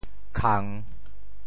潮州发音 潮州 kang3
khang3.mp3